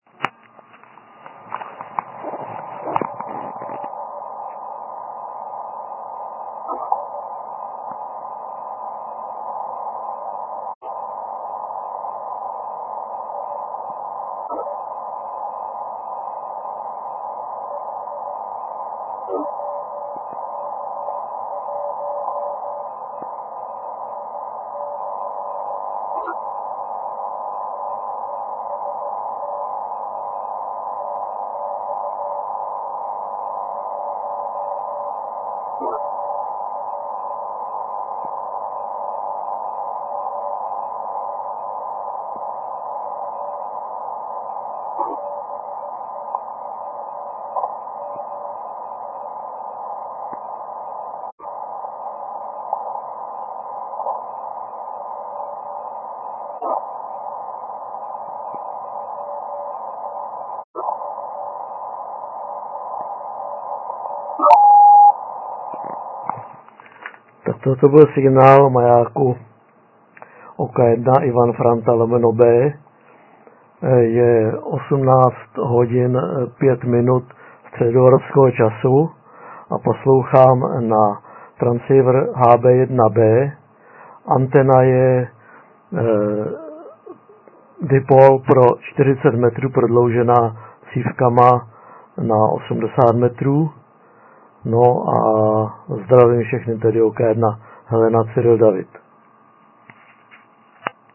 Poslouchal maják na HB-1B TCVR.